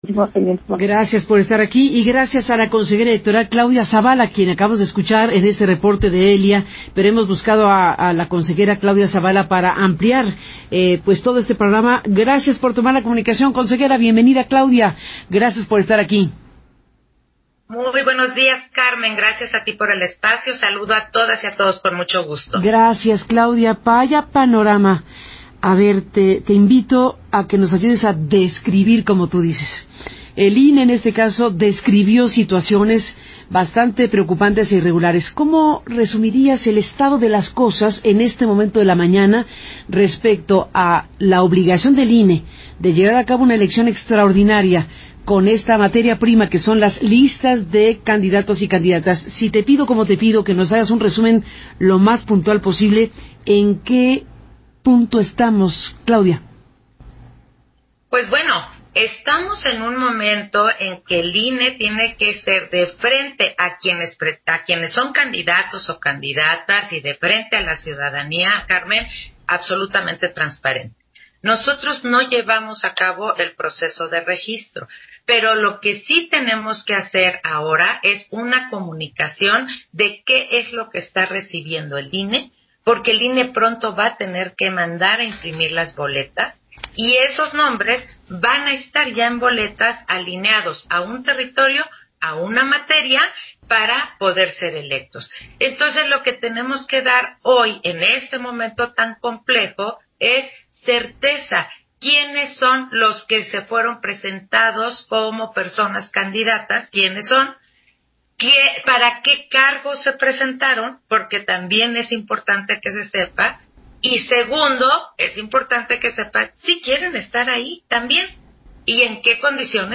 Entrevista de la Consejera Electoral Claudia Zavala con Carmen Aristegui para Grupo Radio Centro